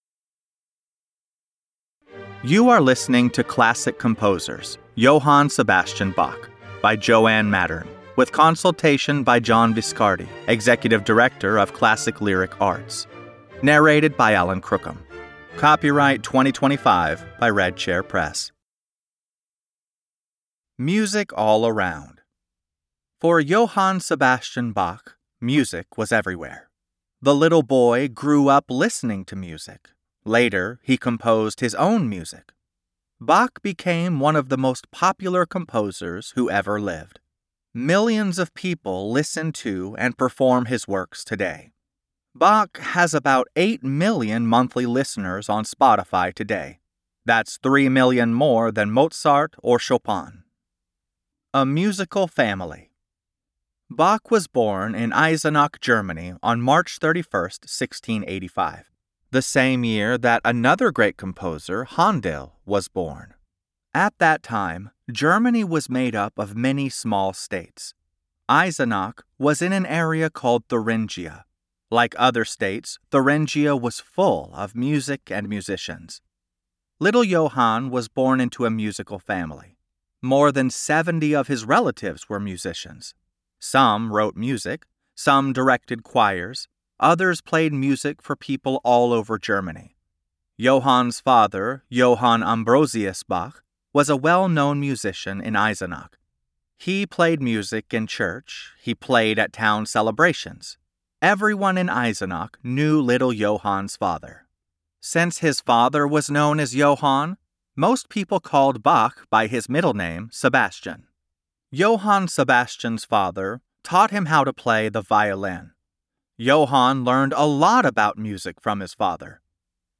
Reading Johann Sebastian Bach (Classical Composers)